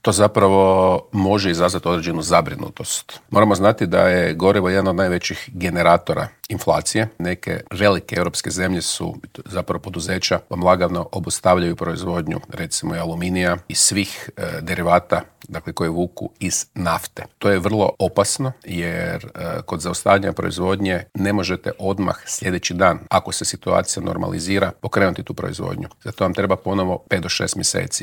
Predsjednik SDP-a Siniša Hajdaš Dončić u Intervjuu tjedna Media servisa poručio je da je Hrvatska energetski ovisna te da imamo najniže zalihe plina u Europskoj uniji.